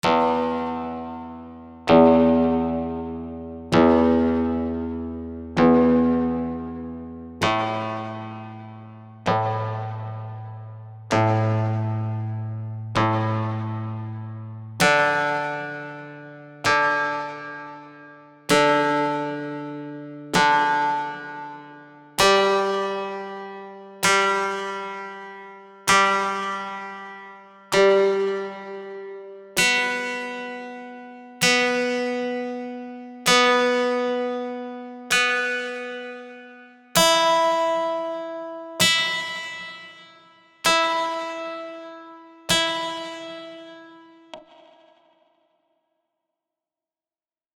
Nastrojona gitara
Nastrojona-gitara-E-A-D-G-B-E-l1e3259z.mp3